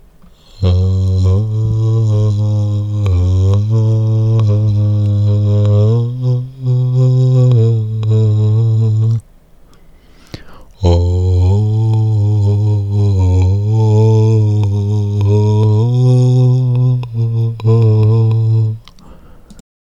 Ачх пения на гласной А на улыбке и в коробочке
1. на улыбке 2. в коробочке Вопрос: Какое ачх лучше с точки зрения информативности?